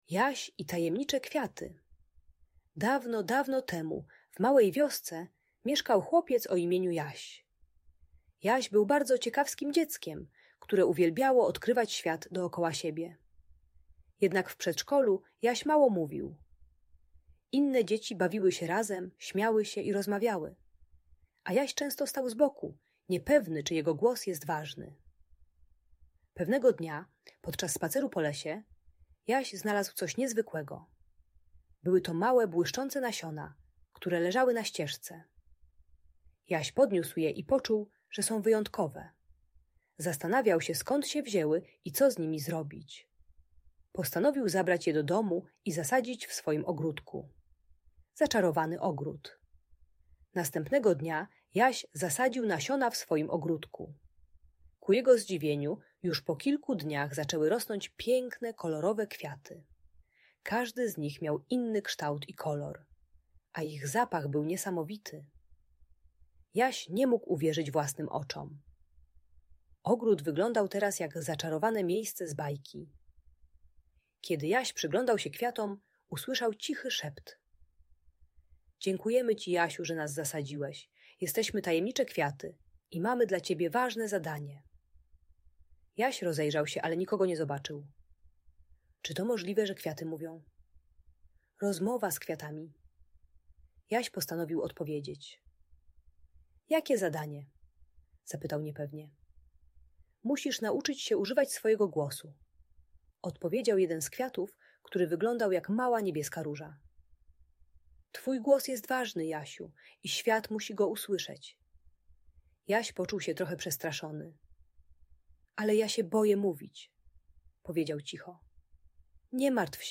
Historia Jasia i Tajemniczych Kwiatów - Audiobajka